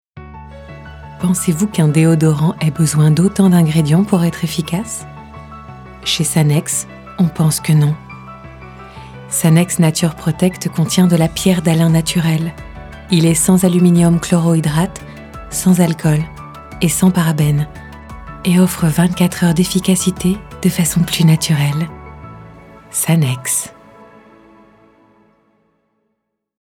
Voix off
25 - 60 ans - Mezzo-soprano